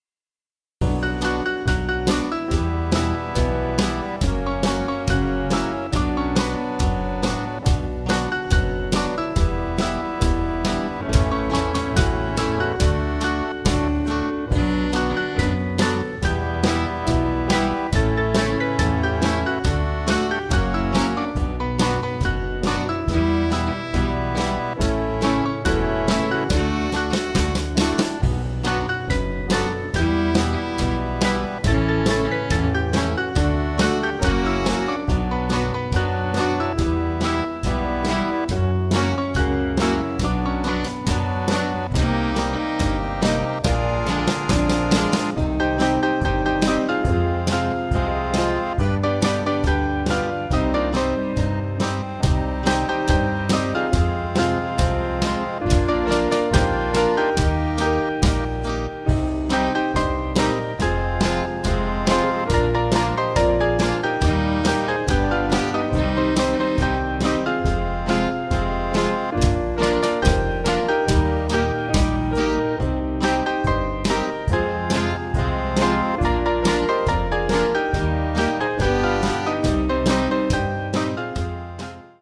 Small Band
Jewish Feel